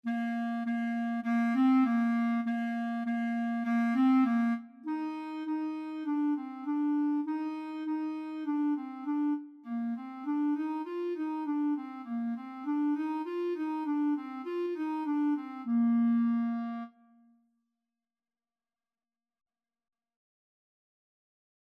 2/4 (View more 2/4 Music)
Bb4-F5
Clarinet  (View more Beginners Clarinet Music)
Classical (View more Classical Clarinet Music)